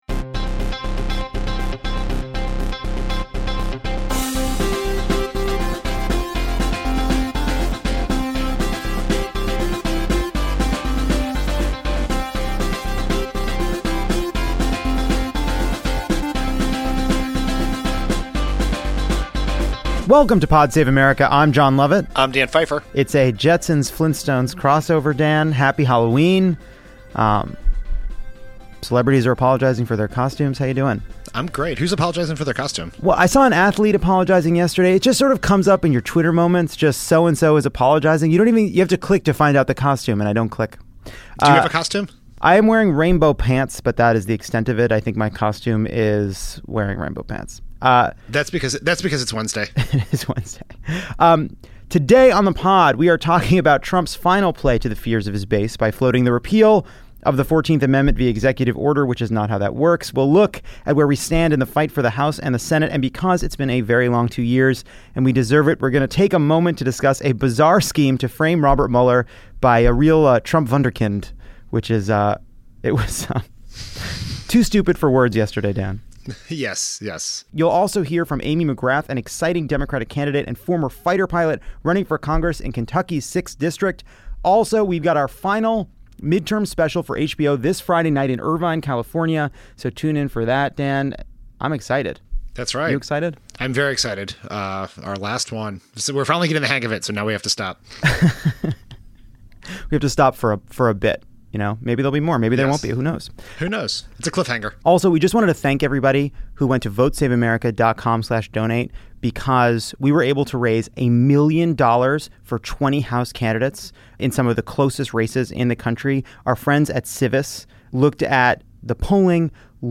With six days to go before Election Day, Trump floats repealing the 14th amendment via executive order, the most racist member of the Republican caucus, Steve King, faces a backlash, and we enter the home stretch with a huge number of districts in play. Plus, one of Trump’s dumbest supporters attempts to frame Robert Mueller, which goes poorly for him. And Kentucky congressional candidate and former fighter pilot Amy McGrath joins to talk about her experience and her race for the House.